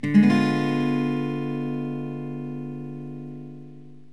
Ddim7.mp3